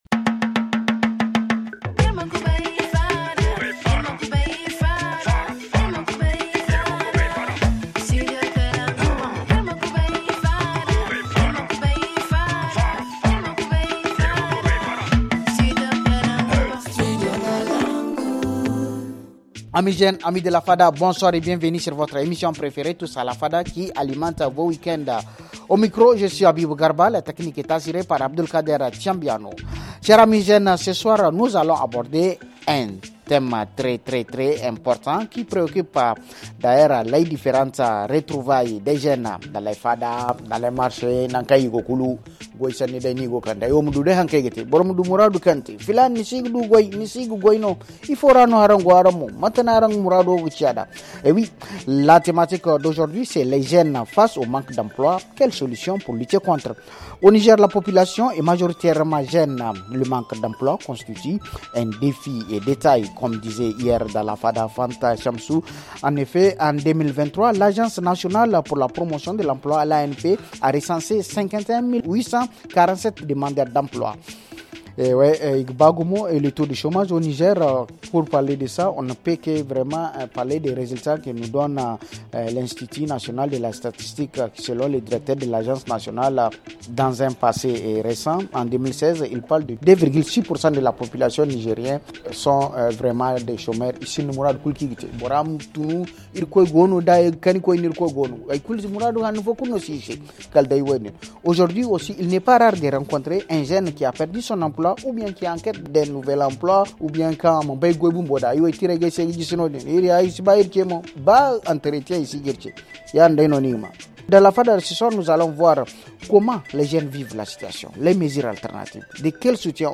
La réponse à toutes ces questions avec nos amis jeunes de la fada installée ce soir au siège du conseil régional des jeunes de Niamey.